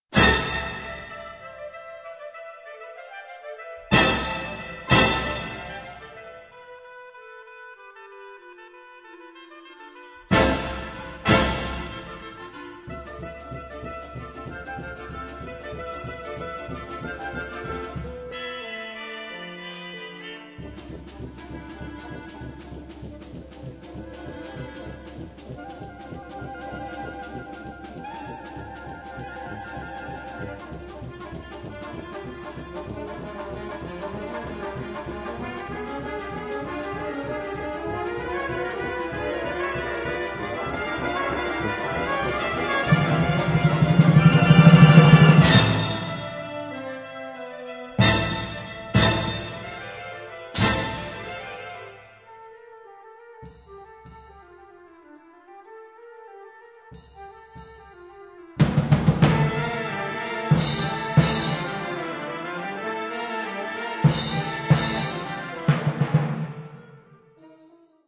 Kategorie Blasorchester/HaFaBra
Unterkategorie Zeitgenössische Musik (1945-heute)
Besetzung Ha (Blasorchester)